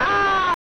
msx_die.ogg